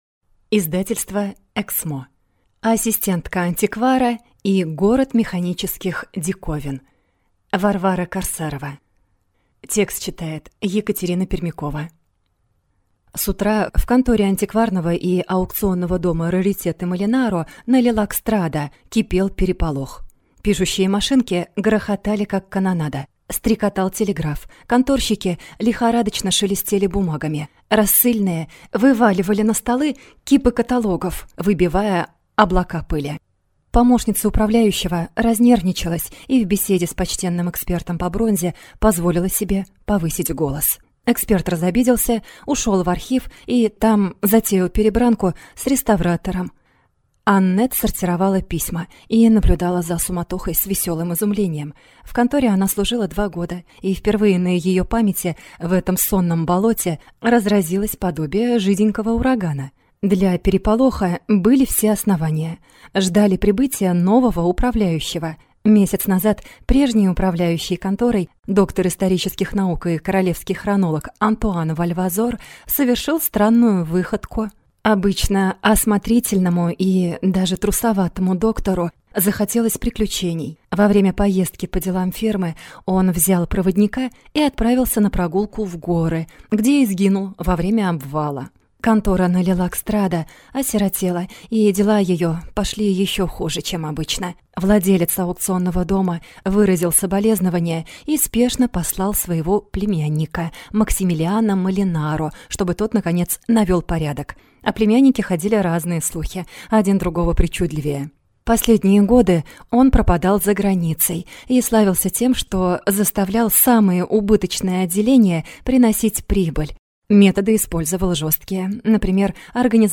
Аудиокнига Ассистентка антиквара и город механических диковин | Библиотека аудиокниг